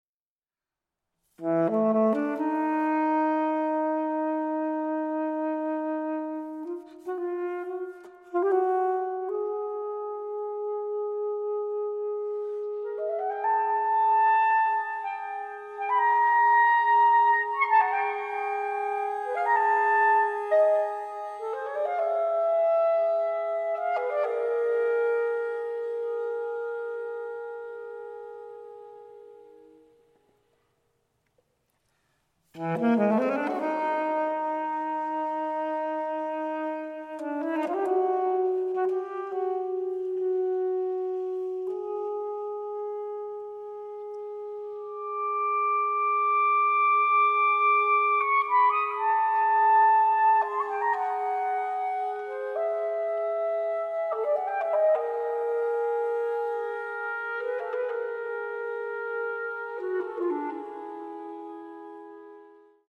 chamber works for various instrumentations